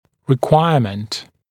[rɪ’kwaɪəmənt][ри’куайэмэнт]требование, необходимое условие